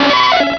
Cri de Nidorina dans Pokémon Rubis et Saphir.